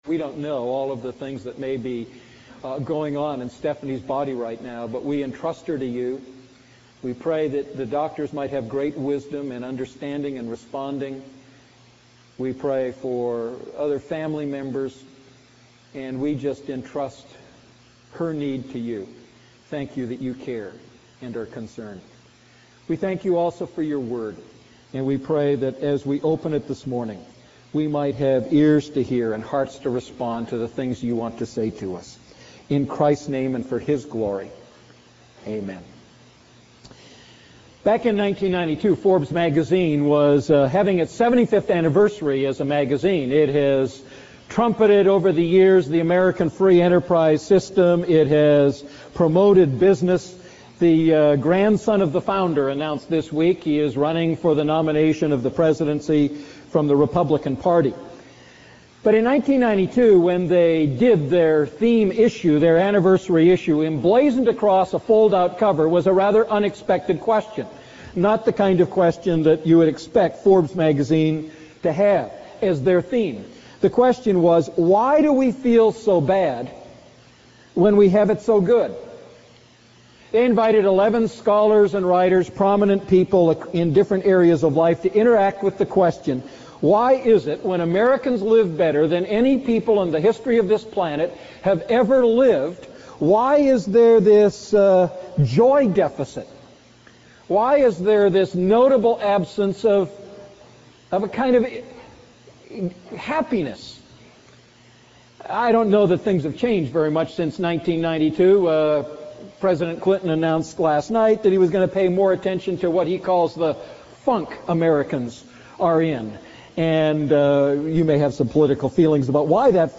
A message from the series "Luke Series II."